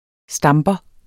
Udtale [ ˈsdɑmbʌ ]